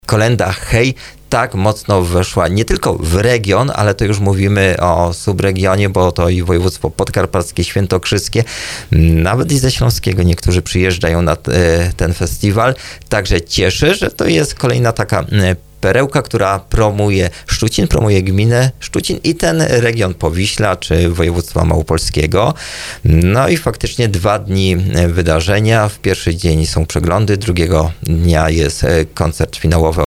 Burmistrz Tomasz Bełzowski przyznaje, że ‘Kolęda Hej!’ na stałe wpisała się do lokalnej tradycji.